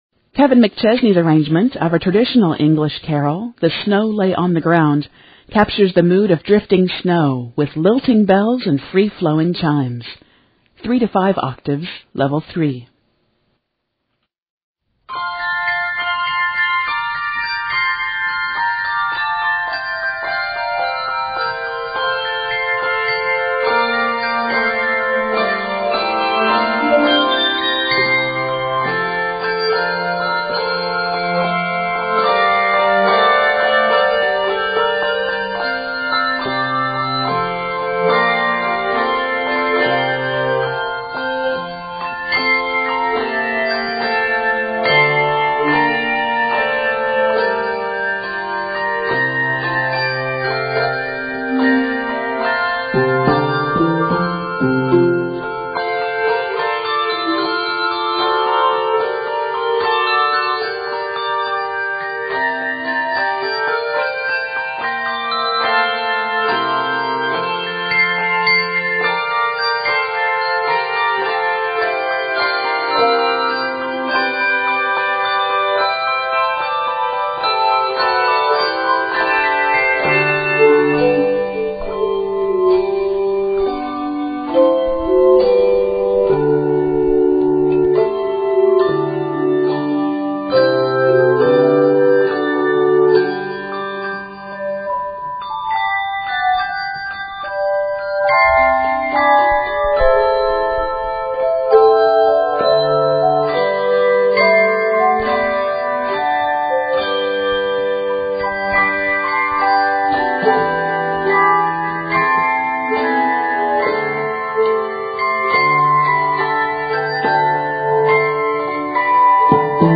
traditional English carol